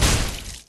PNRailgunImpact.wav